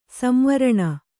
♪ samvaraṇa